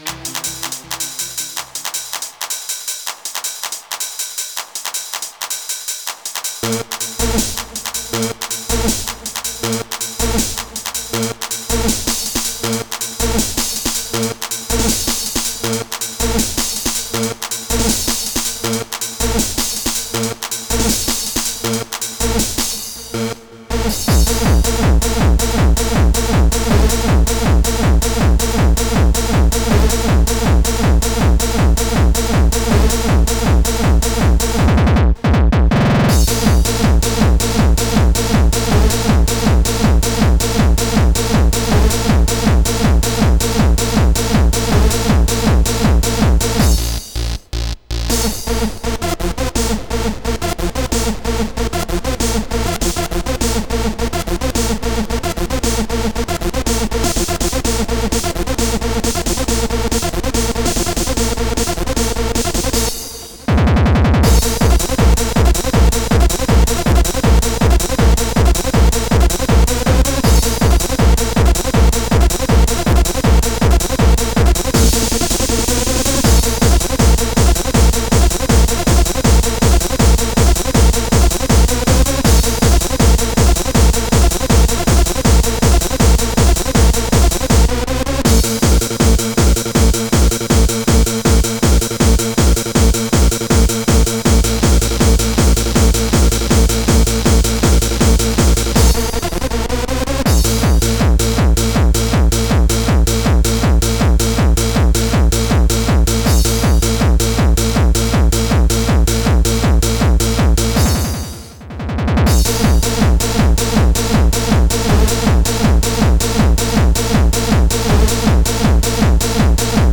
Genre: Hardcore.